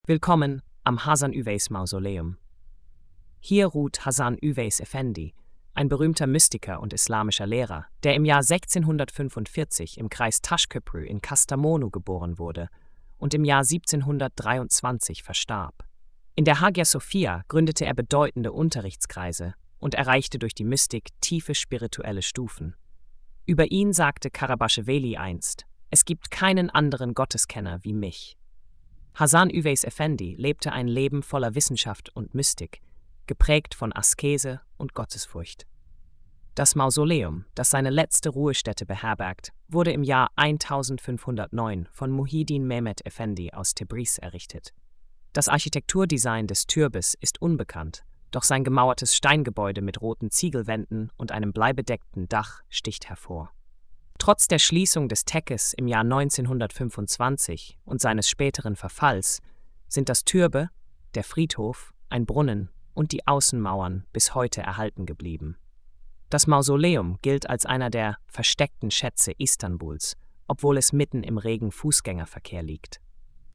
Audio Erzählung :